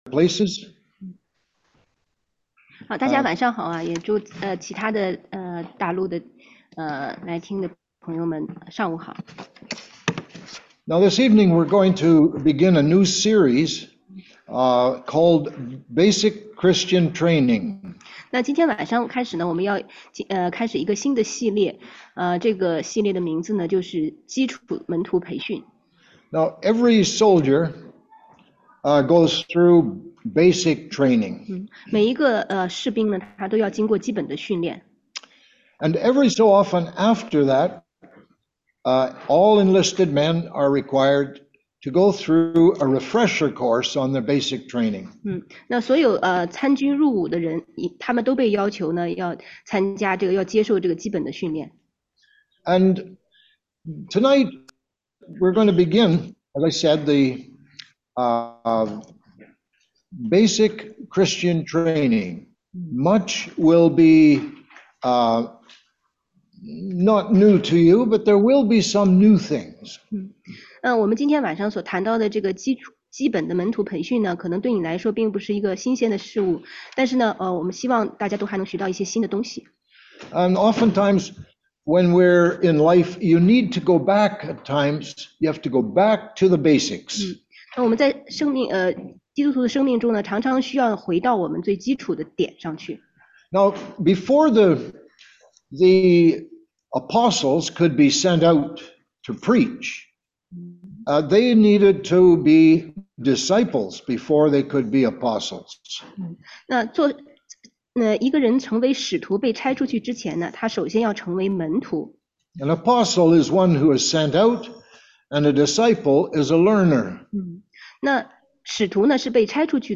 16街讲道录音 - 福音概览
中英文查经